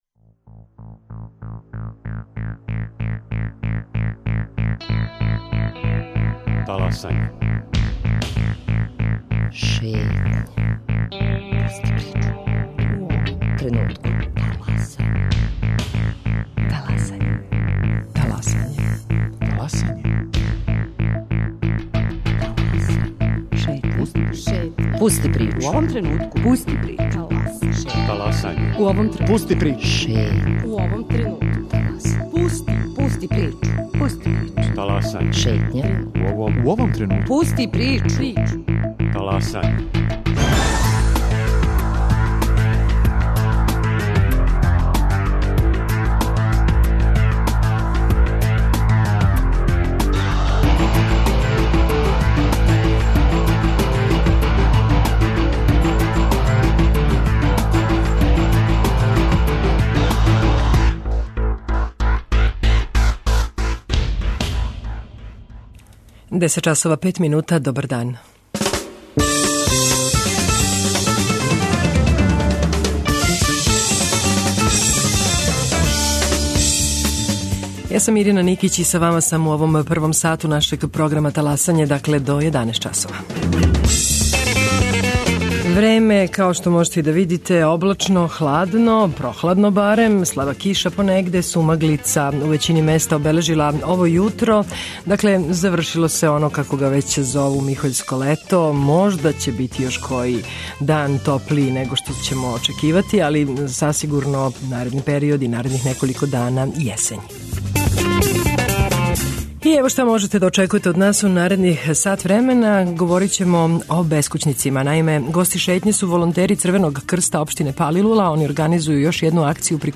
Фестивал "Слободна зона" најавиће организатори, гости данашње Шетње.